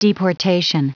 Prononciation du mot deportation en anglais (fichier audio)
Prononciation du mot : deportation